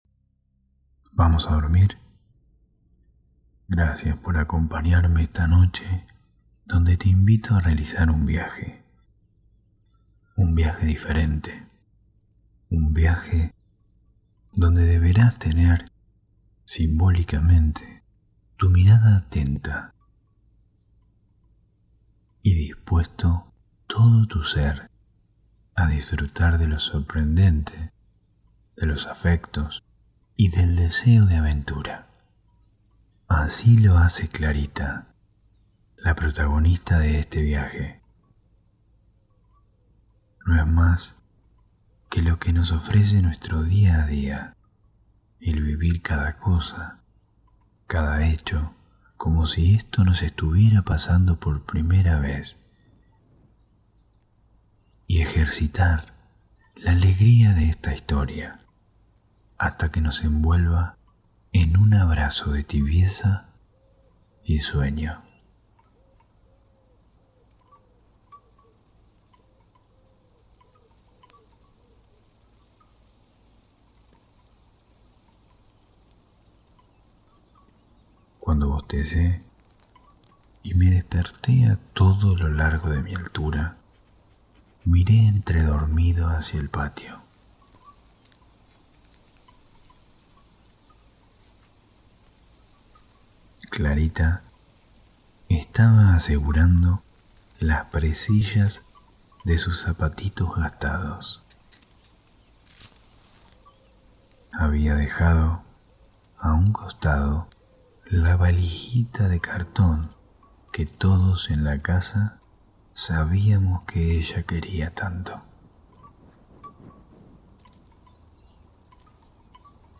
Cuentos ASMR para dormir - Como un fuego de artificio